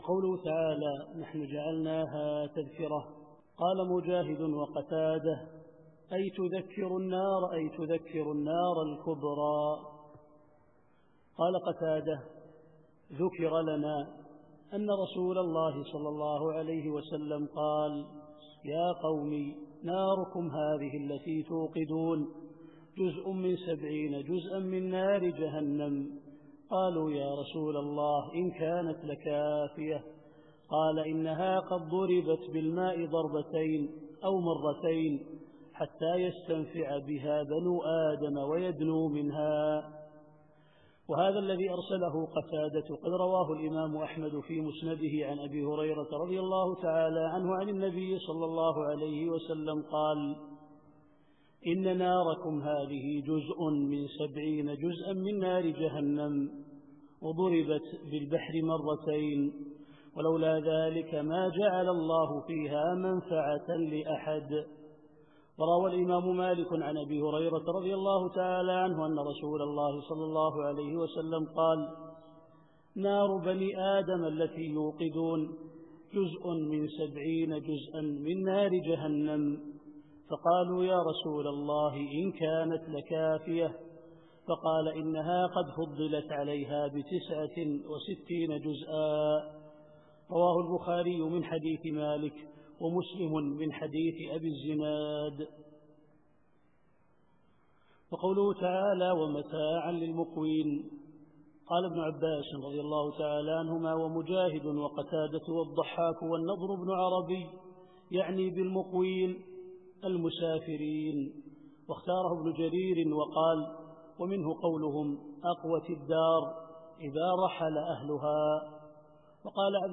التفسير الصوتي [الواقعة / 73]